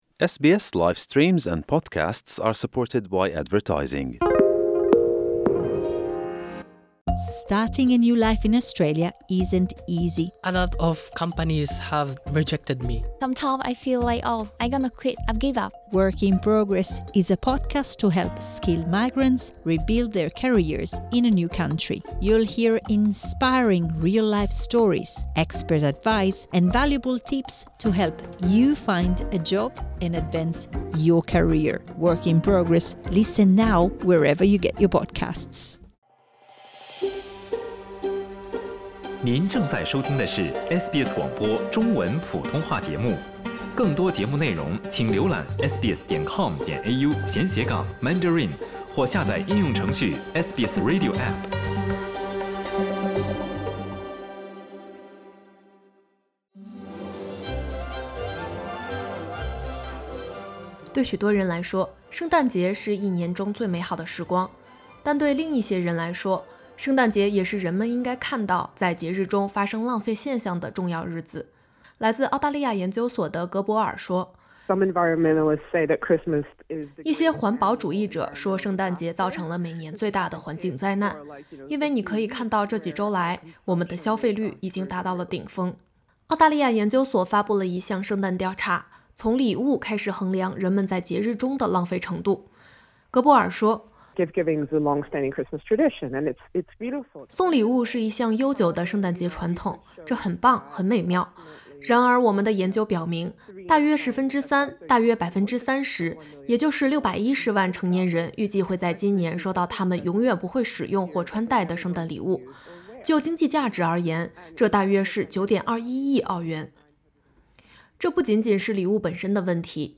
澳关爱协会研究显示，今年圣诞节澳大利亚人将使用超过15万公里的包装纸，足以环绕地球赤道近四圈。许多人越来越担心，因节日原因大量的礼物、过剩的食品以及其他圣诞相关的物品正在被浪费掉。点击音频收听详细报道。